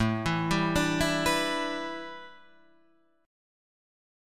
A511 chord